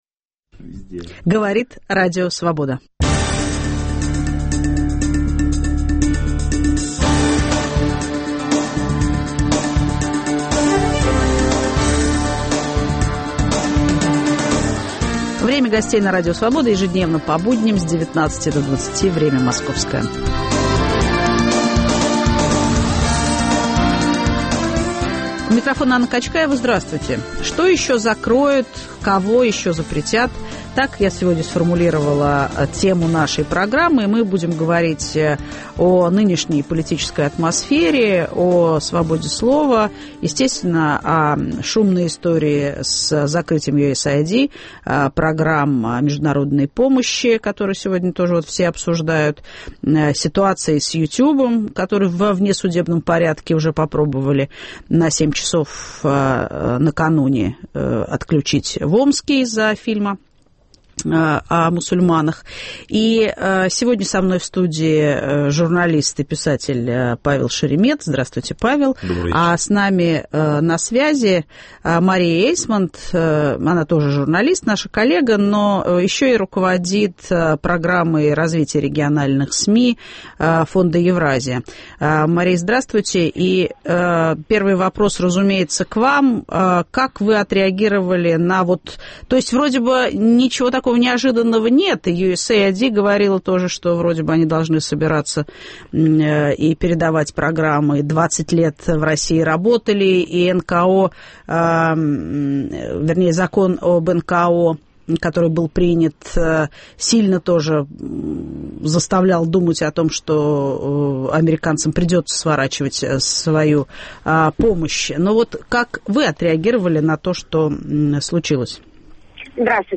Ведет программу Анна Качкаева